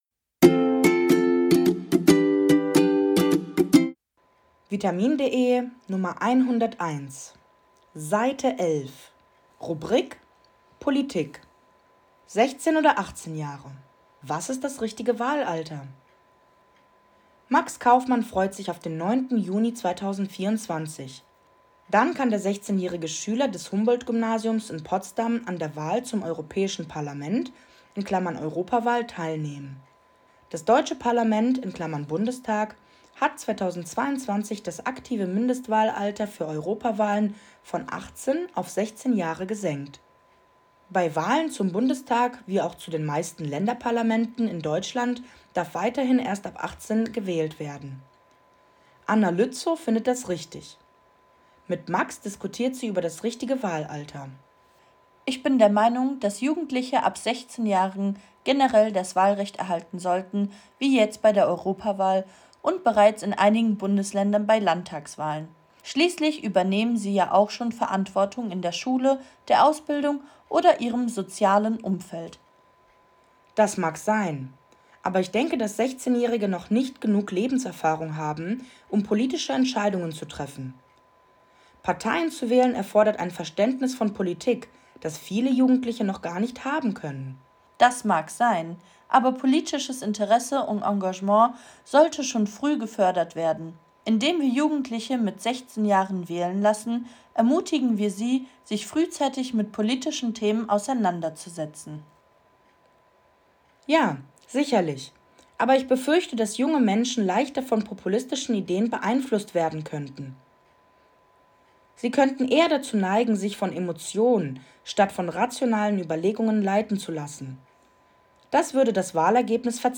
Audiodatei (Hörversion) zum Text